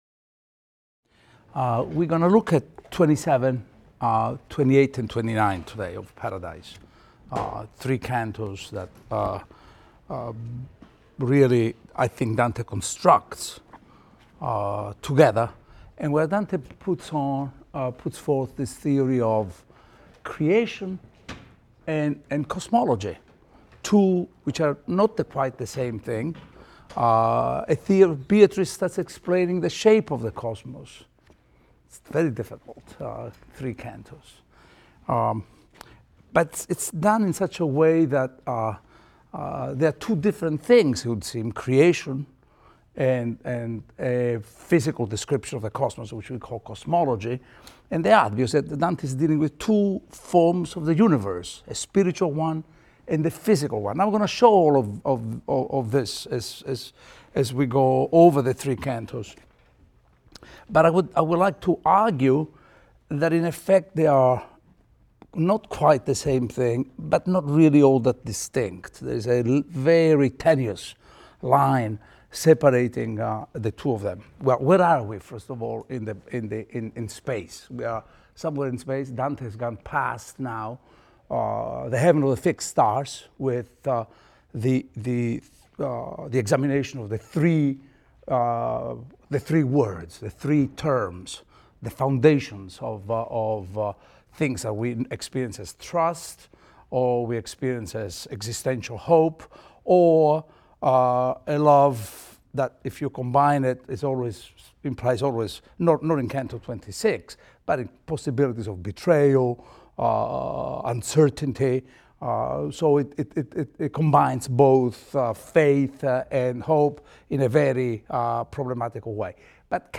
ITAL 310 - Lecture 22 - Paradise XXVII, XXVIII, XXIX | Open Yale Courses